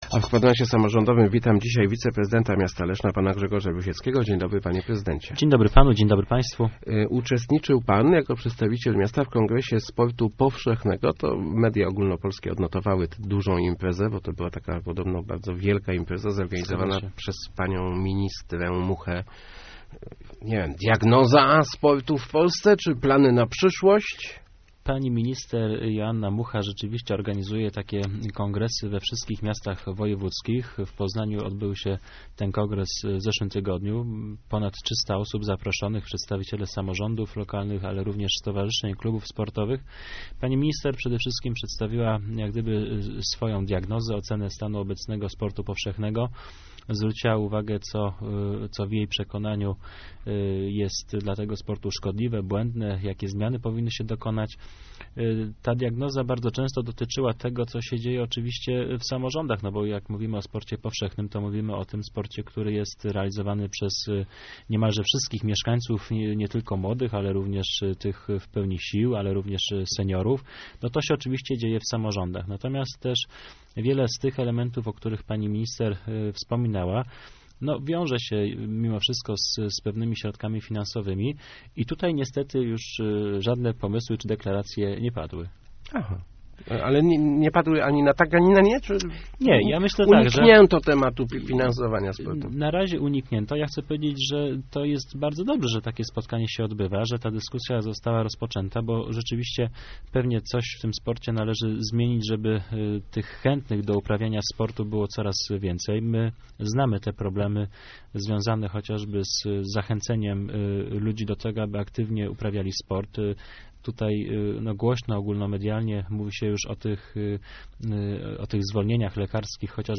Gościem Kwadransa był wiceprezydent Grzegorz Rusiecki.